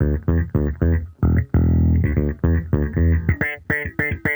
Index of /musicradar/sampled-funk-soul-samples/110bpm/Bass
SSF_JBassProc2_110E.wav